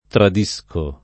tradire v.; tradisco [ trad &S ko ], -sci